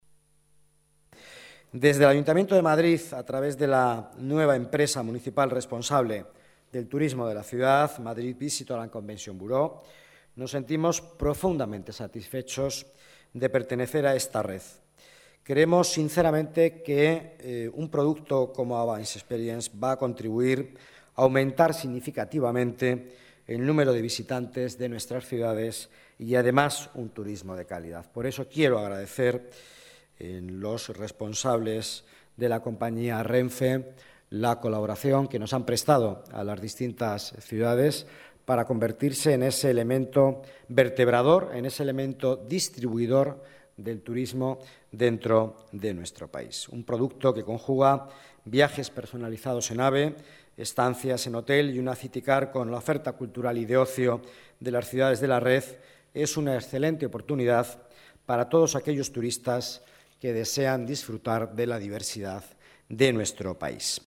Nueva ventana:Declaraciones del vicealcalde de Madrid, Miguel Ángel Villanueva